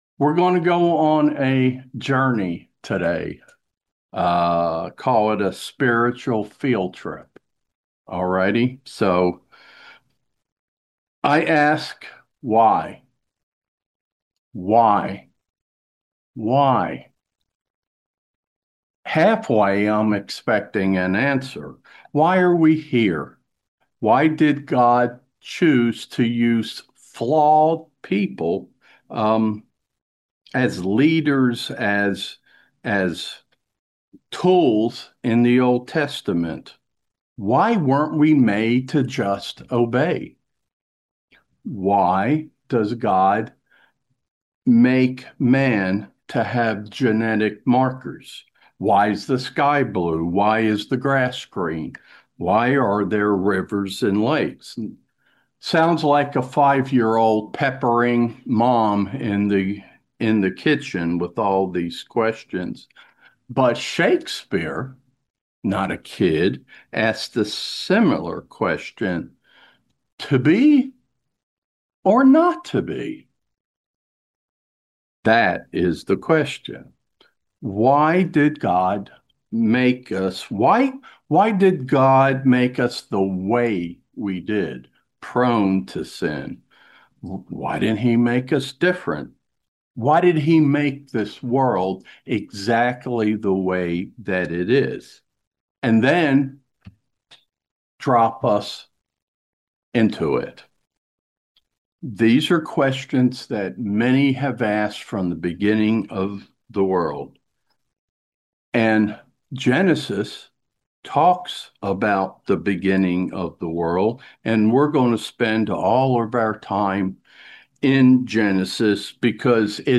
In this sermon series we will analyze how God has always been intimately and actively involved in His creative plan for all mankind. Along the way we will learn that God has a very detailed and orderly process He follows in order to work out His divine plan to save the world and give human beings eternal life.